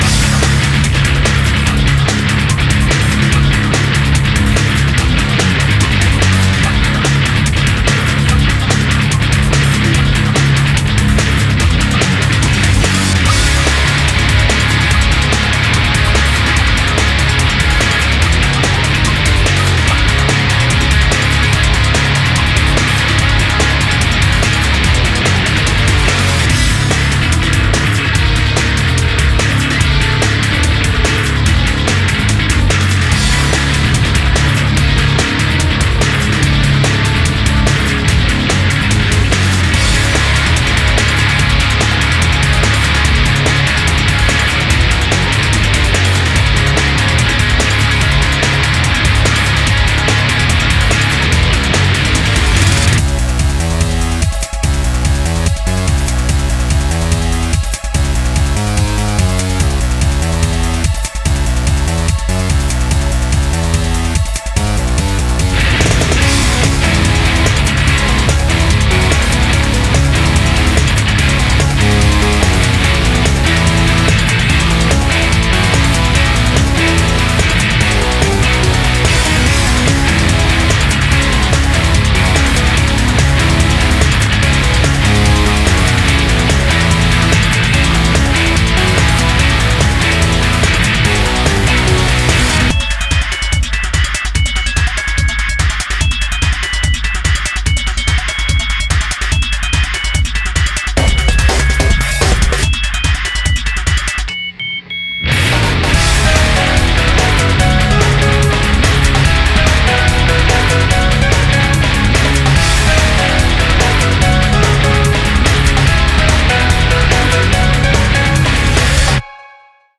BPM145-290
Audio QualityMusic Cut
remix